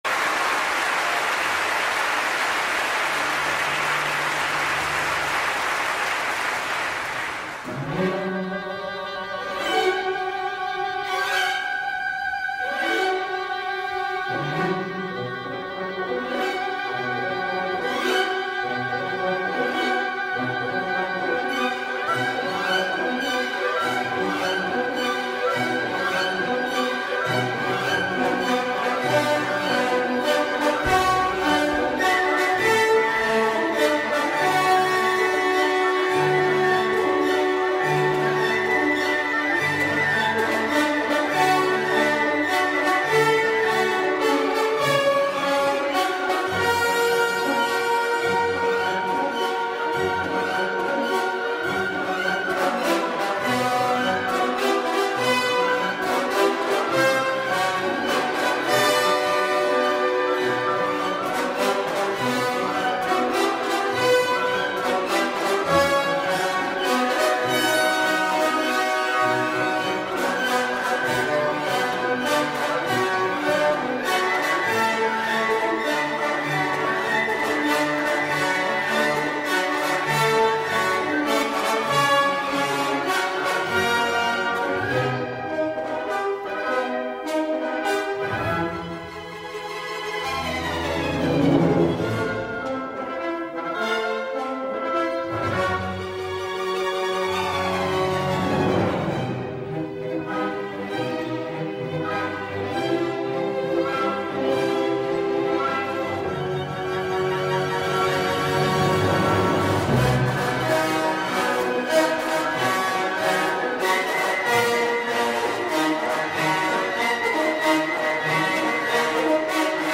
Today, we are going to learn about music that makes us feel SCARED.